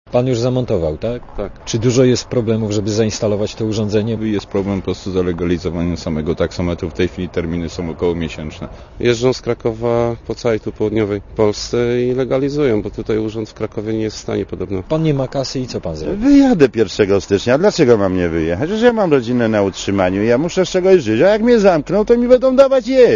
Posłuchaj rozmowy reportera Radia Zet z taksówkarzami